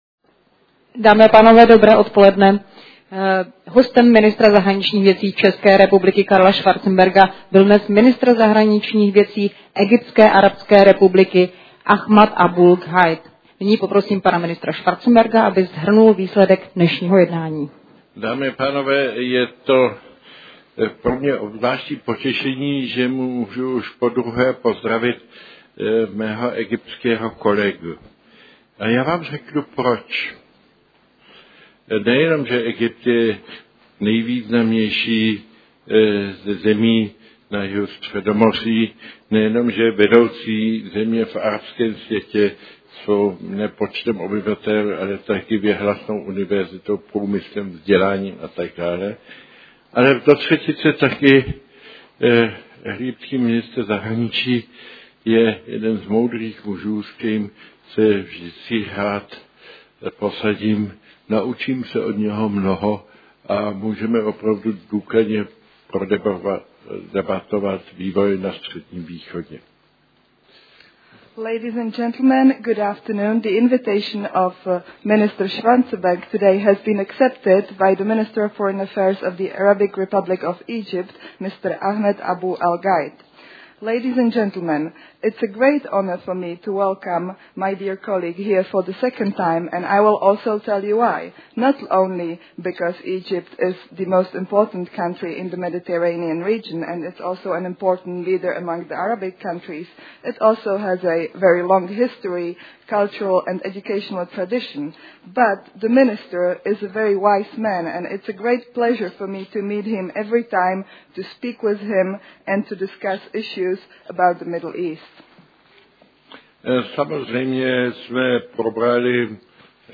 TK ministra zahraničních věcí Egypta Ahmeda Abú el-Gheita